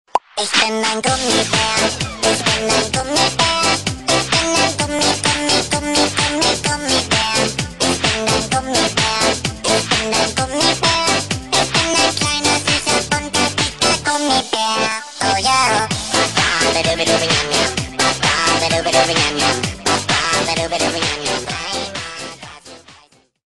Категория: Попса